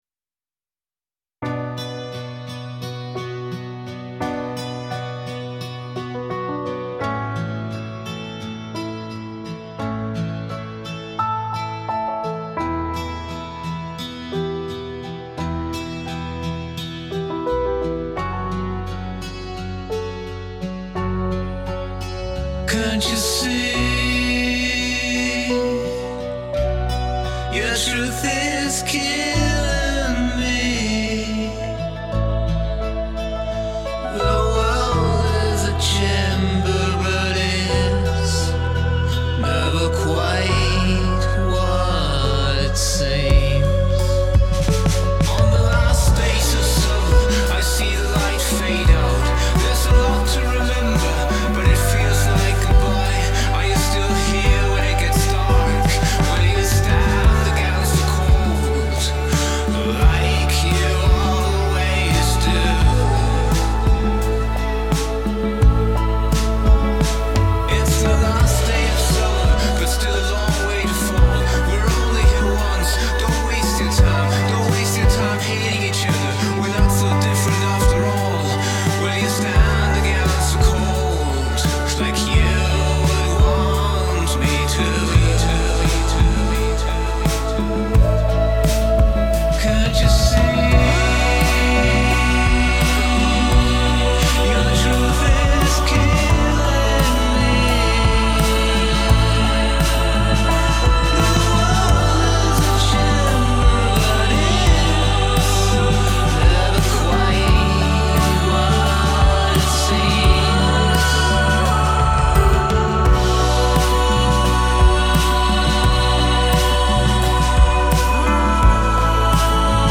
der dem song den Rest gegeben hat. also Lyrics und Vocals.